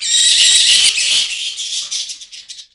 sonar.wav